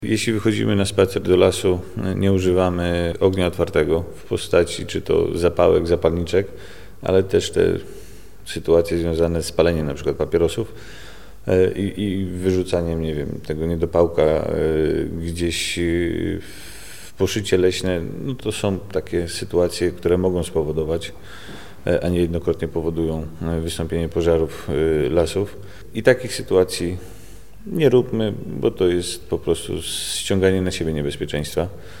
strazak-1.mp3